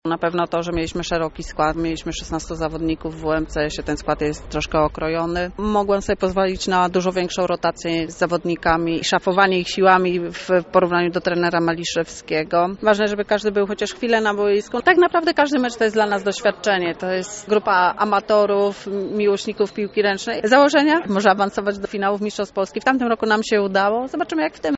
W rozmowie pomeczowej podkreśliła, że był to jeden z powodów ich zwycięstwa: